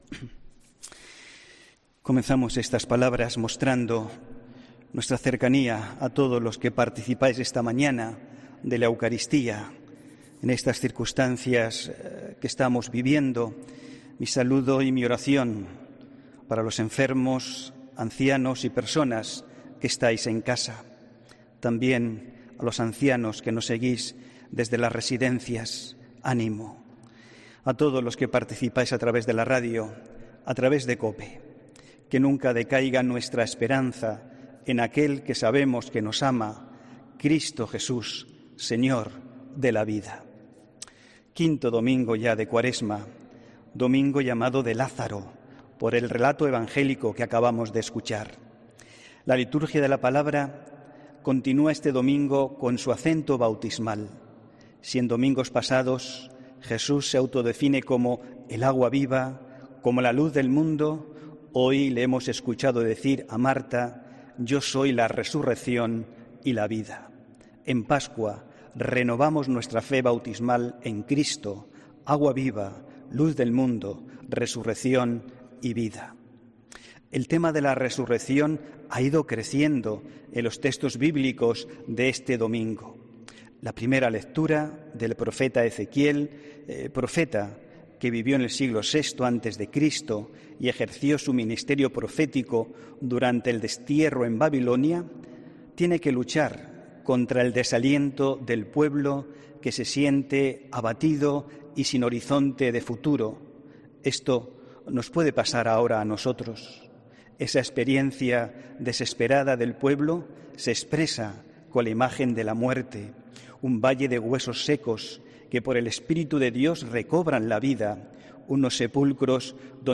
HOMILÍA 29 MARZO 2020